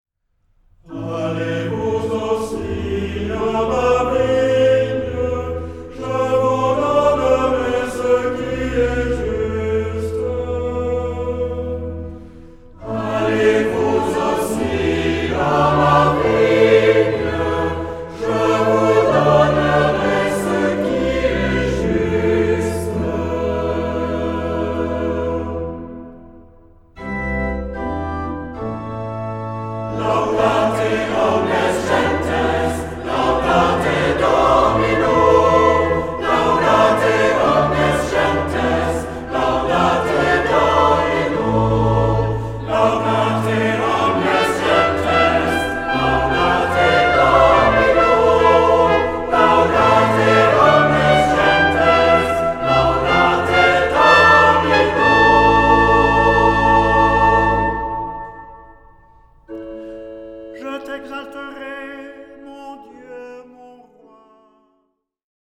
Genre-Style-Forme : Sacré ; Tropaire ; Psaume
Caractère de la pièce : recueilli
Type de choeur : SATB  (4 voix mixtes )
Instruments : Orgue (1) ; Instrument mélodique (1)
Tonalité : la majeur